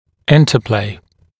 [‘ɪntəpleɪ][‘интэплэй]взаимодействие (например, между дугой и пазом брекета)